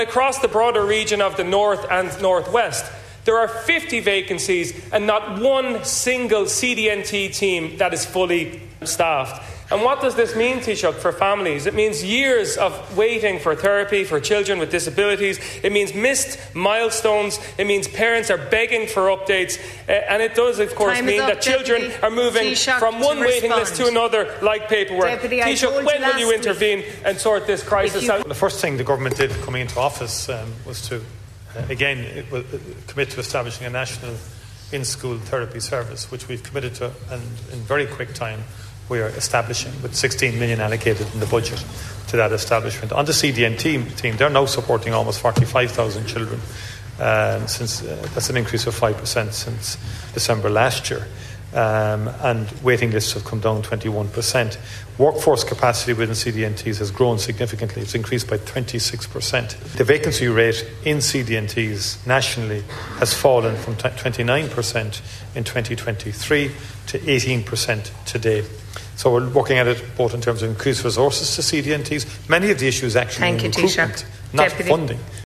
Michael Martin told the Dail that the vacancies in counties like Donegal and Mayo reflect a problem with recruitment rather than a shortage of funding, saying extra college places are being created in a bid to increase the number of qualified professionals in the field.
He was questioned by Mayo Deputy Paul Lawless, who said there is a crisis in the CDNT sector across the whole north west region………